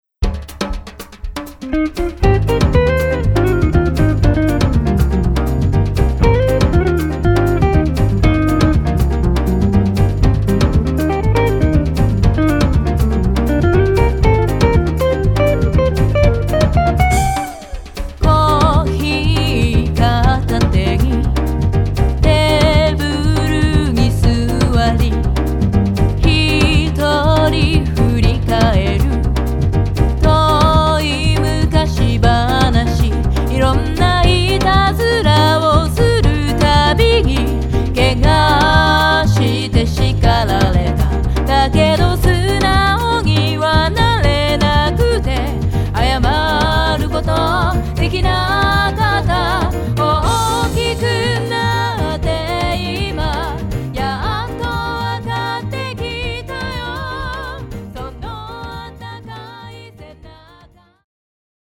ボサノヴァバージョン